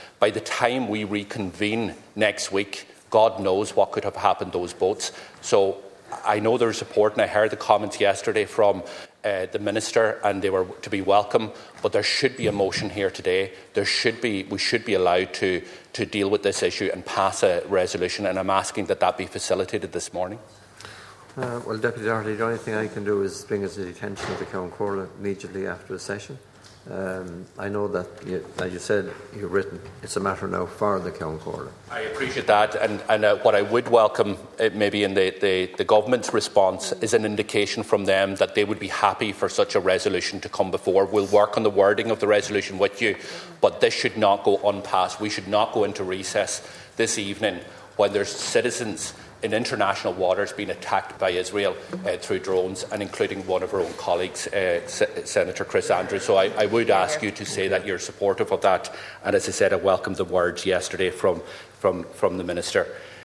Sinn Féin Deputy Leader Pearse Doherty told the Dáil chamber that they cannot break up for the week without having put a motion in place: